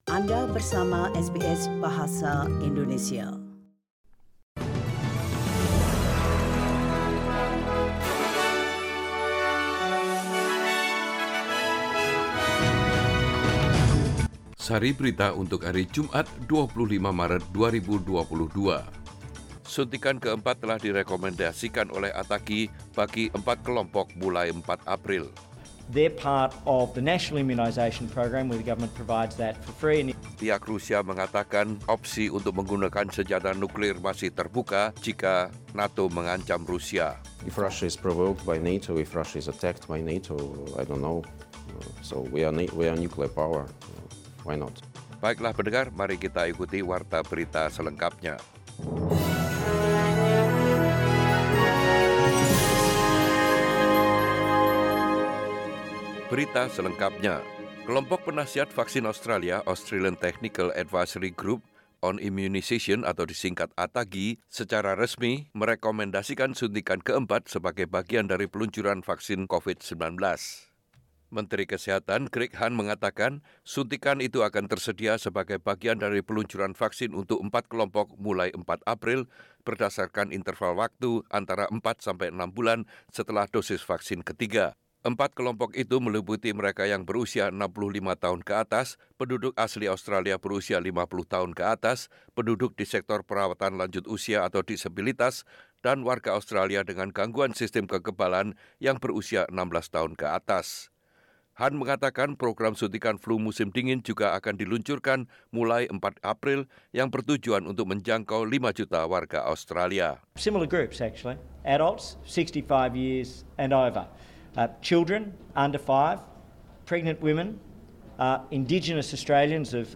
Warta Berita Radio SBS Program Bahasa Indonesia - 25 Maret 2022